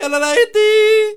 JODLER     3.wav